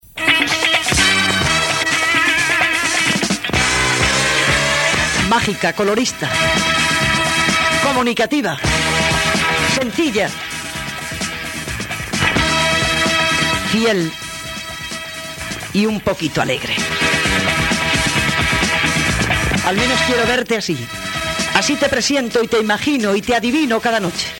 Fragment del programa, en el qual Encarna Sánchez s'adreça a la seva audiència
Entreteniment